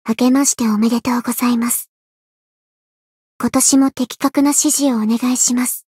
灵魂潮汐-阿卡赛特-春节（相伴语音）.ogg